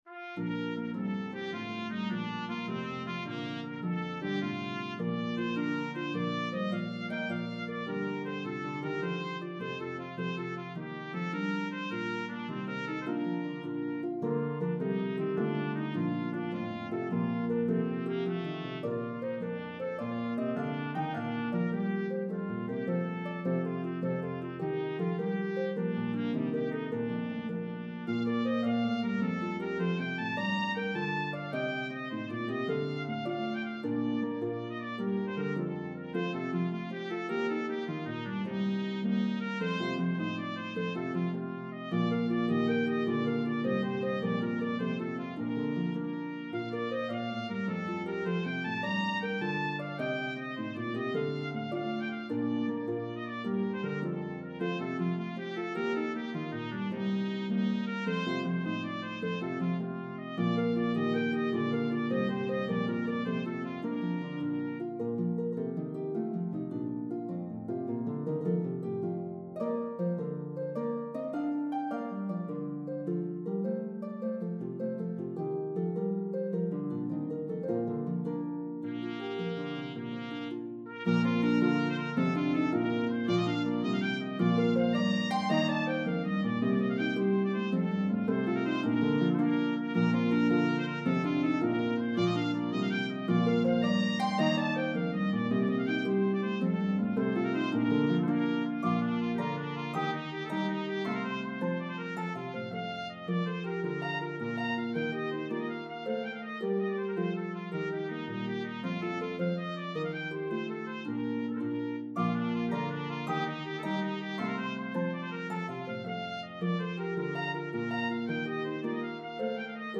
The medley progresses through 3 keys.
The Harp part is playable on either Lever or Pedal Harps.